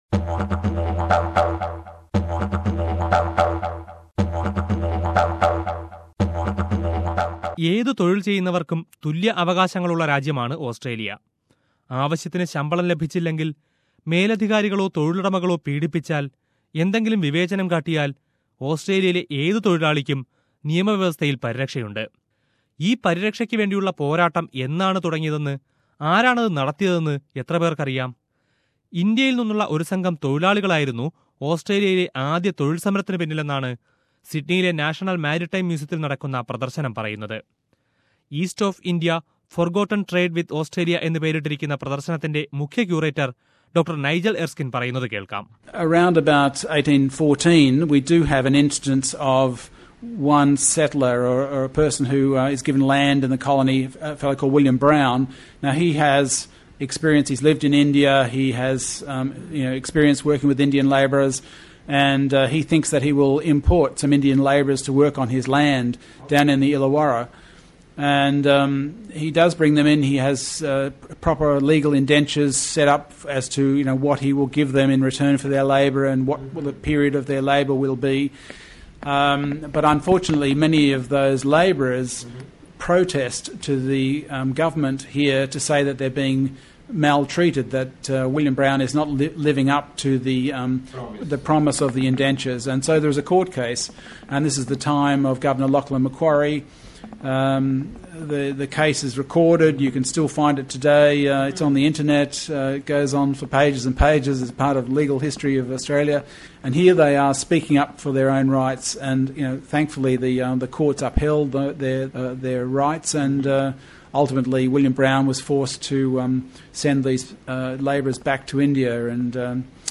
Listen to a feature about the exhibition